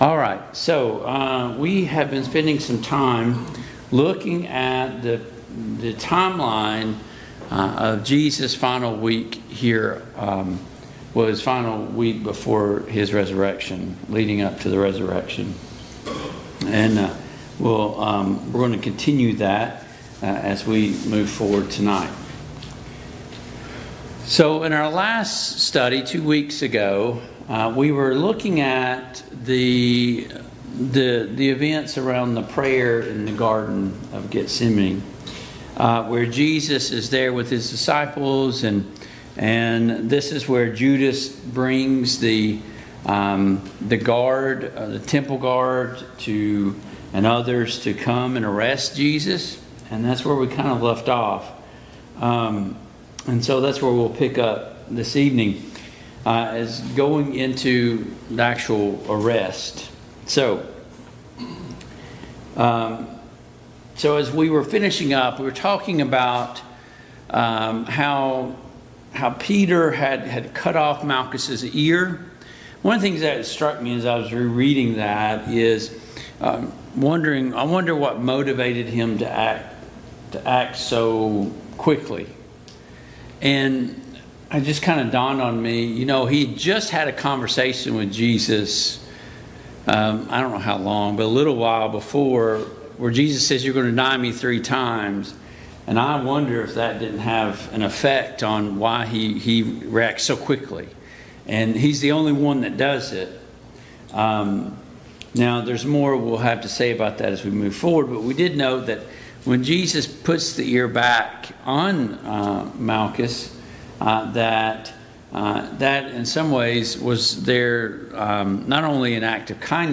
Mid-Week Bible Study Download Files Notes Topics: Jesus' Arrest and Mock Trial « 1.